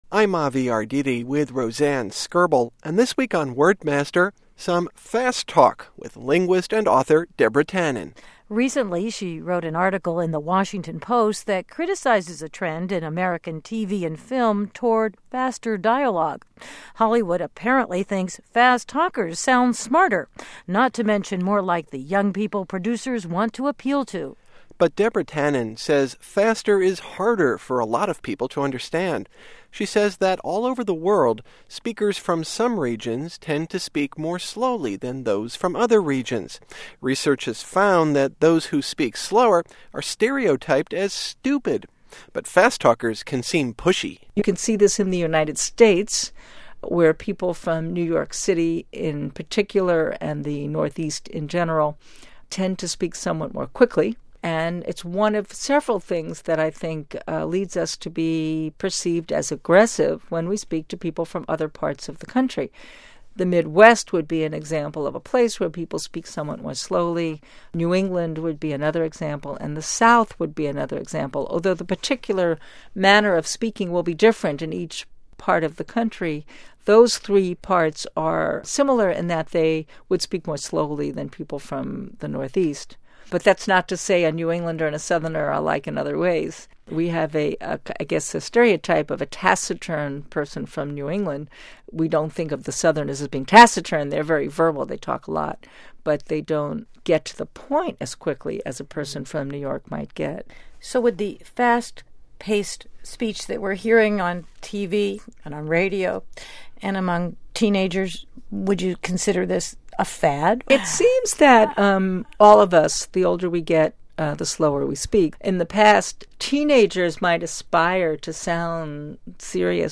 Broadcast on "Coast on Coast": January 23, 2003